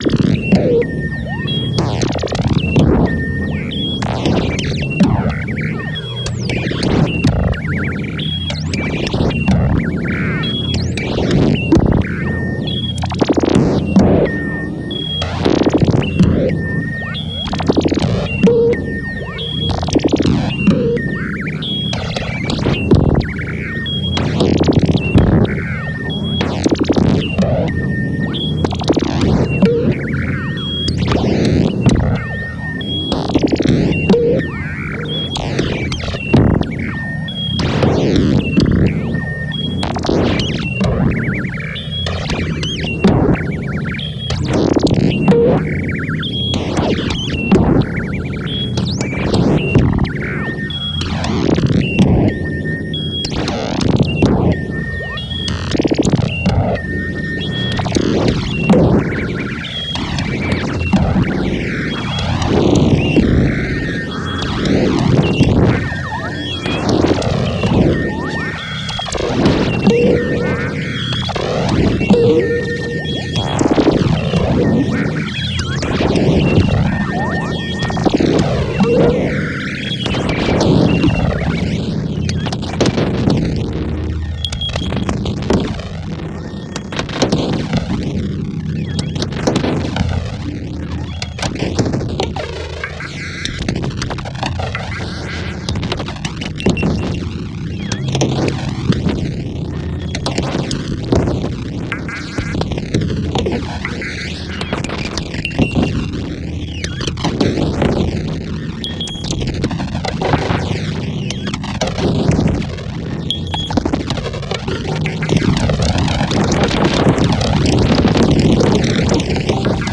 声音能量密度大，信号在不同时域里深挖兔子洞。
游浮的振荡器幽灵，释放饱和的锯齿状的声音颗粒，倾泻在洛夫克拉夫特式的宇宙图像中。